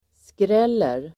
Uttal: [skr'el:er]